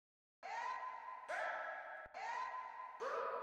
描述：简单的Trap样本包，包括Kick N Snare、HiHats、Synth N Brass和Chants。
Tag: 140 bpm Trap Loops Vocal Loops 590.80 KB wav Key : Unknown